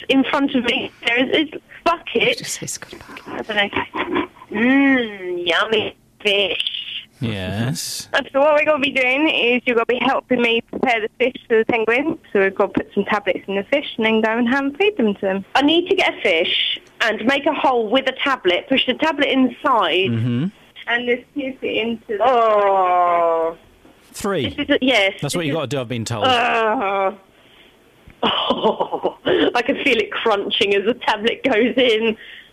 At the Penguin Pen in Paradise Wildlife Park, Broxbourne...